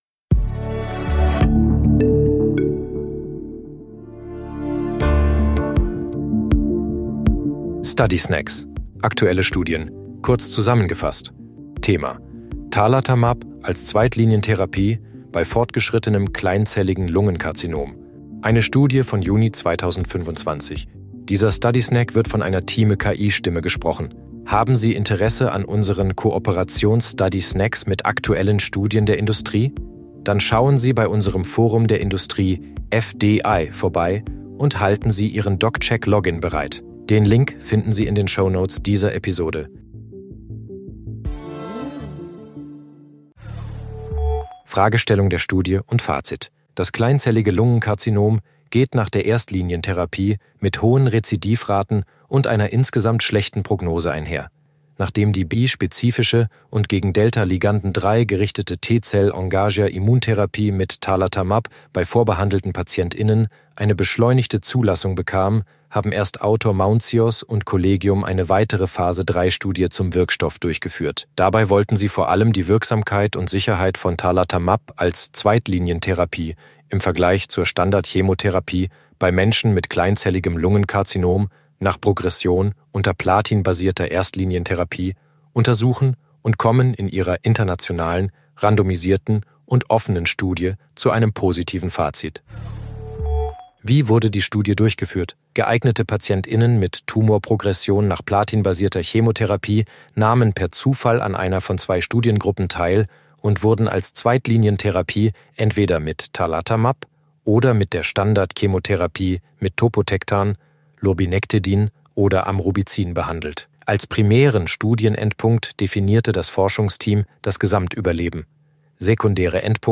In diesem Audio sind mit Hilfe von künstlicher
Intelligenz (KI) oder maschineller Übersetzungstechnologie